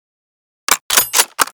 bolt.ogg.bak